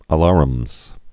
(ə-lärəmz, -lăr-)